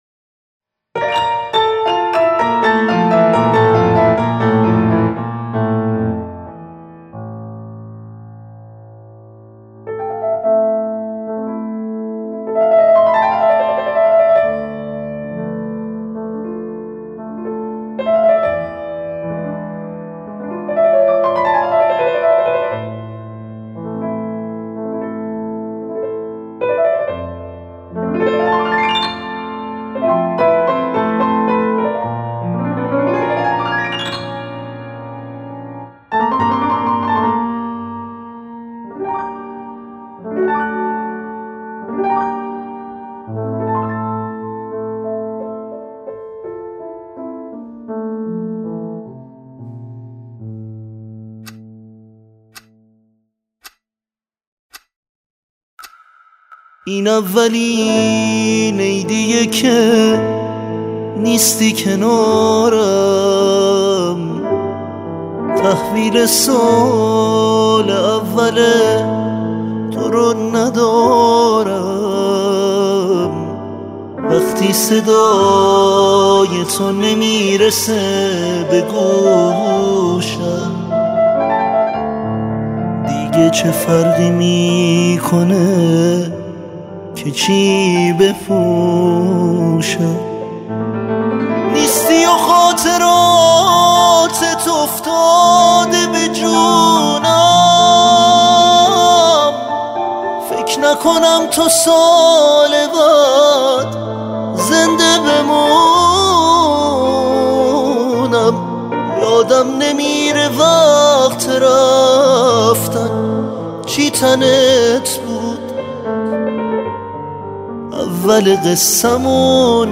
ژانر: پاپ
توضیحات: ترانه های شاد مخصوص نوروز